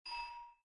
Soft-Notifications - Bell - LowDing
Bell ding Notification SFX Soft UI sound effect free sound royalty free Sound Effects